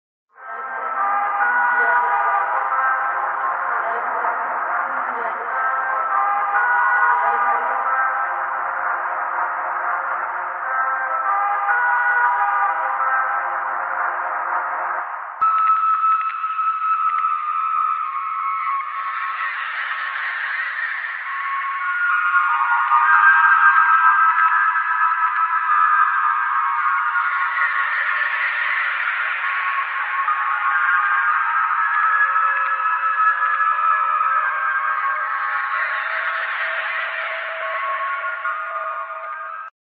distorted trumpets hl2 Meme Sound Effect
distorted trumpets hl2.mp3